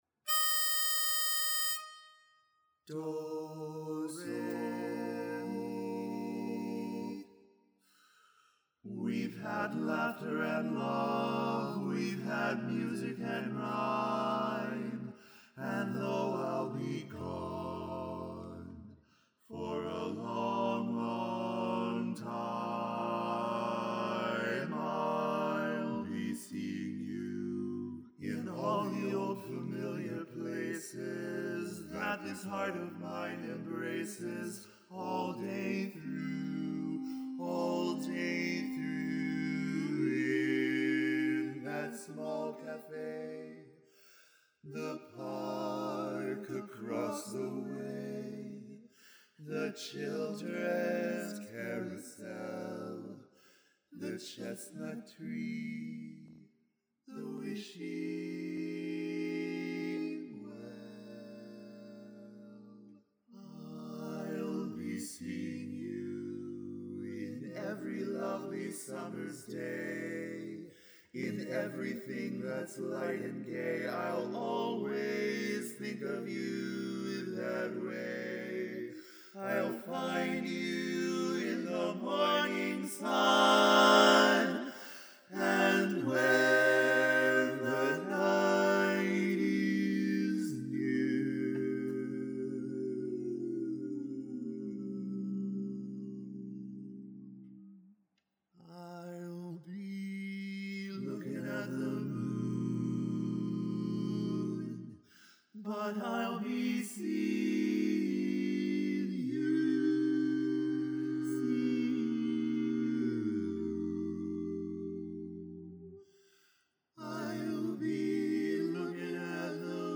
Ballad
Barbershop
E♭ Major